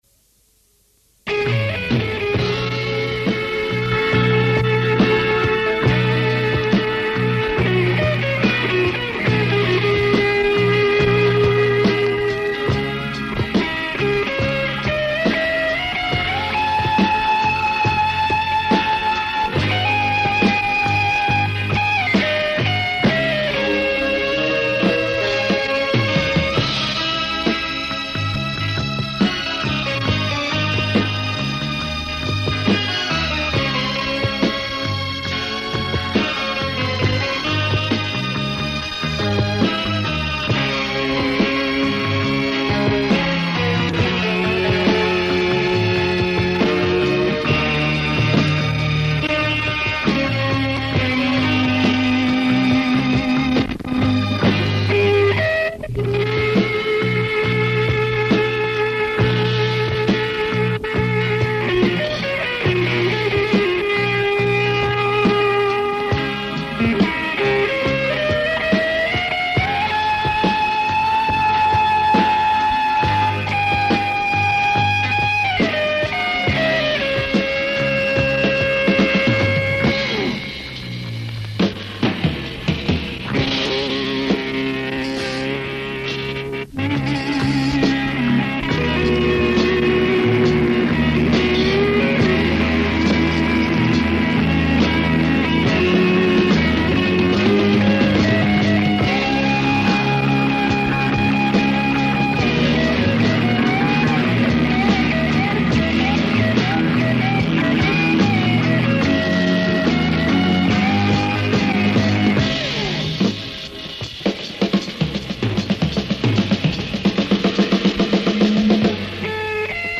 Подскажите, пожалуйста, название и исполнителя этой инструментальной композиции (гитара).
На Сатриани раннего похоже.
Все эти электрогитарные композиции как близнецы-братья.